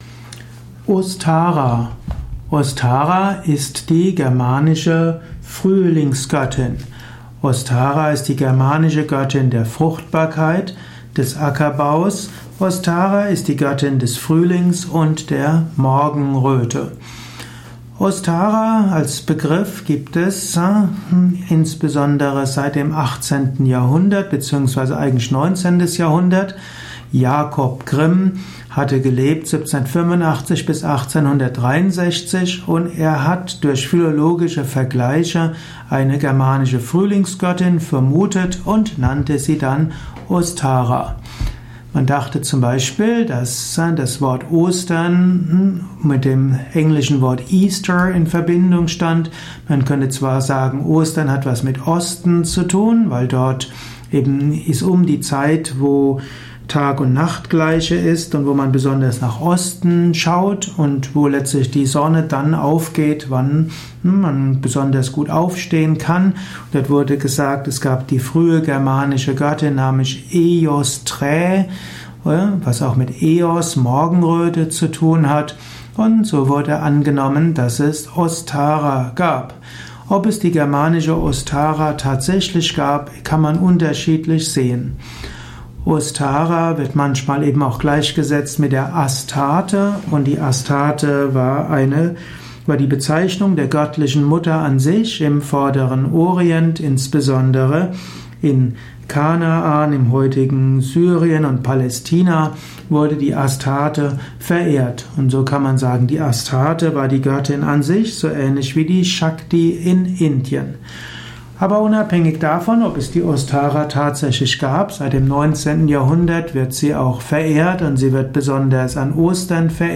Ein Audio Vortrag über Ostara, einer germanischen Göttin. Mit Überlegungen über die Bedeutung von Ostara in der germanischen Mythologie, im germanischen Götterhimmel. Welche Bedeutung hat Göttin Ostara im Vergleich zu anderen Göttern und Göttinnen anderer Traditionen? Dies ist die Tonspur eines Videos, zu finden im Yoga Wiki.